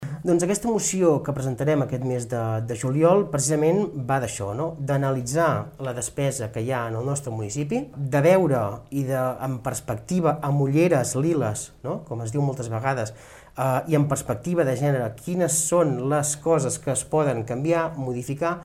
Ho explica el regidor d’ERC, Jordi Romaguera.
L’entrevista sencera al regidor Jordi Romaguera es pot veure al Youtube de Ràdio Tordera.